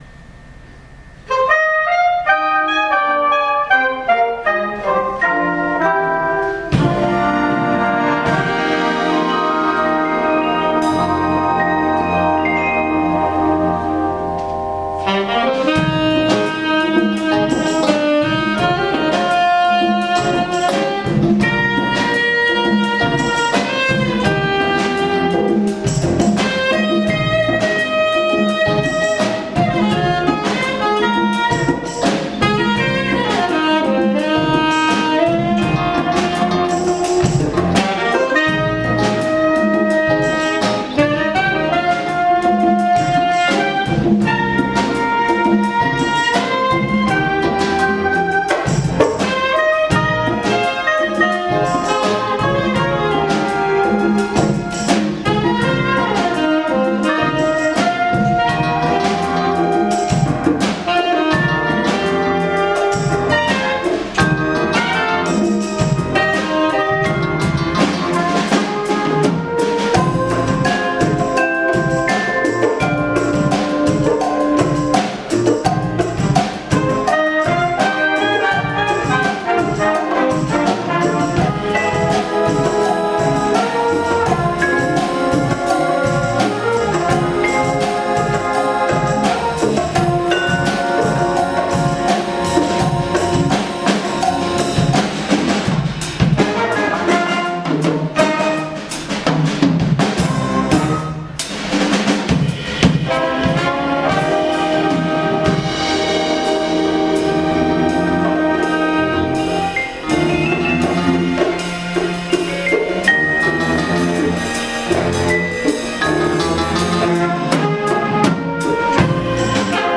Category: Big Band (13 horns, 7 rhythm, + 5 aux ww+str)
Style: Funky Jazz Fuzion
Solos: soprano, tenor 1, trumpet
Instrumentation: big band (4-4-5, rhythm (7)